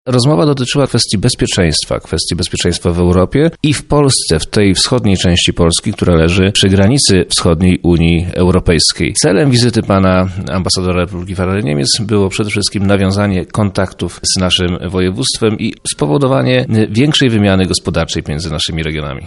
– mówi Przemysław Czarnek, wojewoda lubelski.